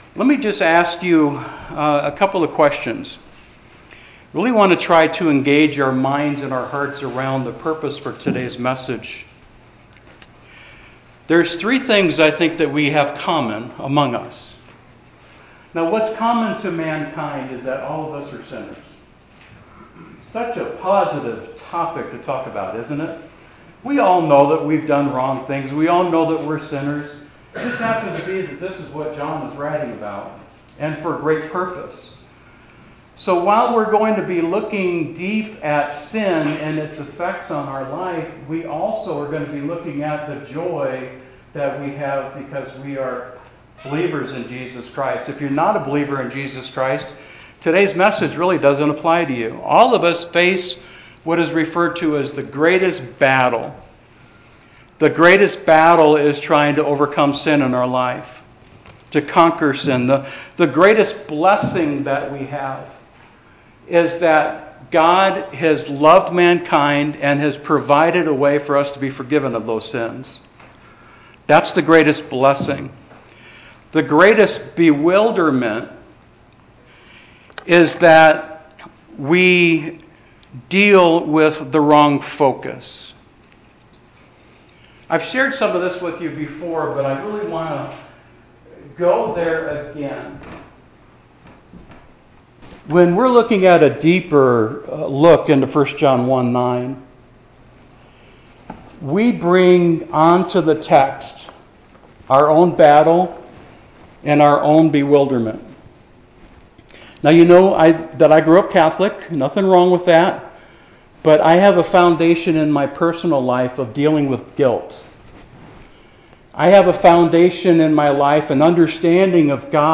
Sermon outline: Our condition (if/then) The Ongoing Confession (ABC’s) The Omnipotent Character (He is, He will) Our condition is sinful, prideful, and fallen.